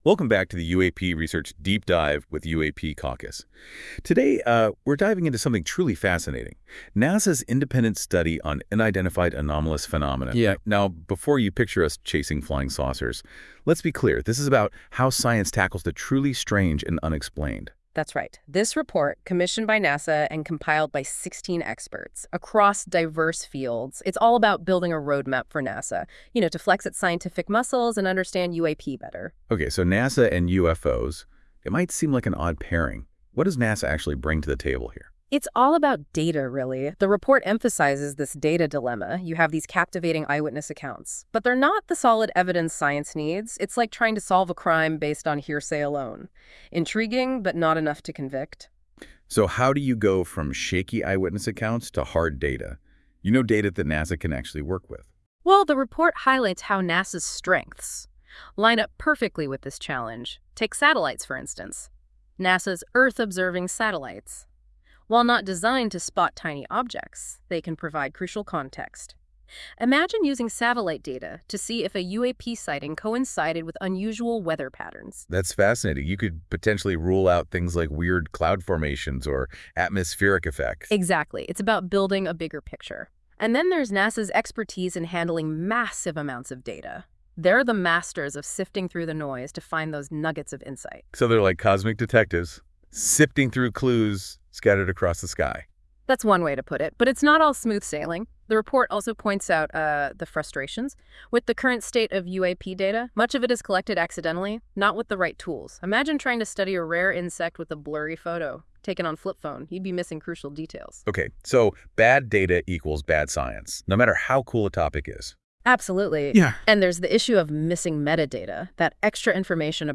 Powered by NotebookLM. This AI-generated audio may not fully capture the research's complexity.